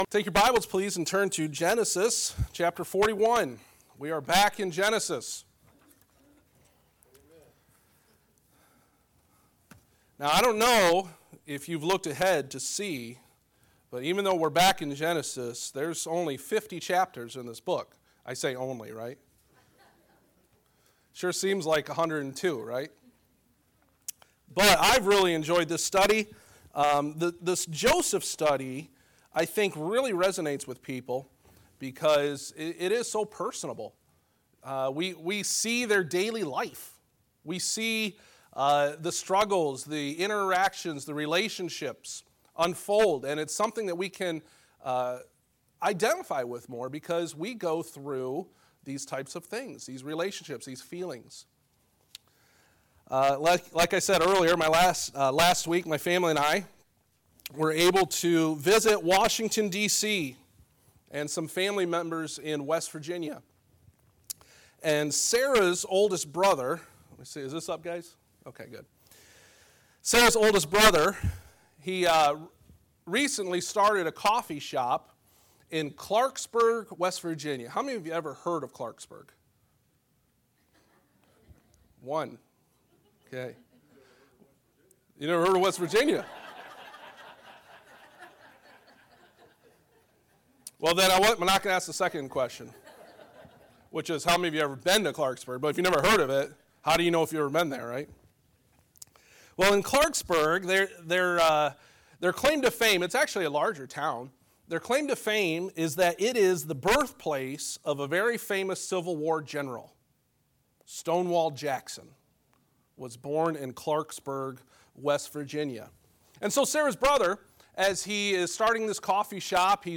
Sermon-8-13-17.mp3